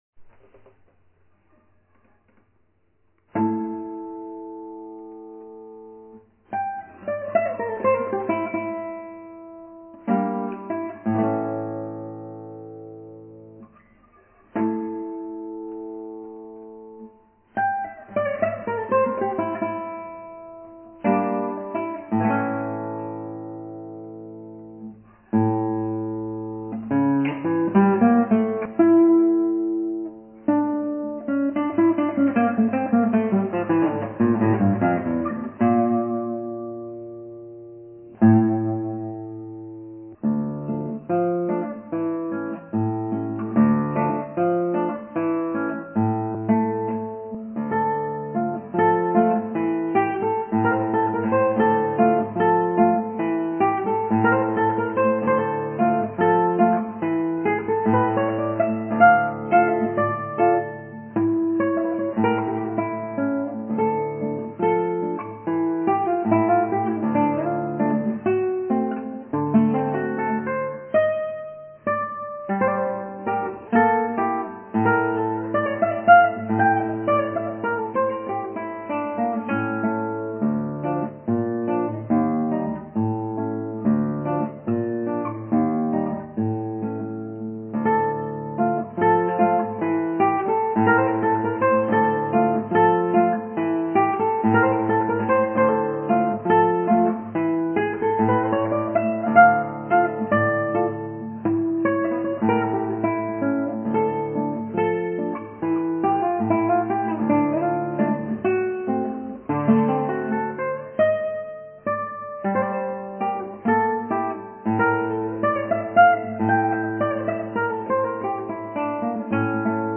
アラビア風奇想曲、マリンで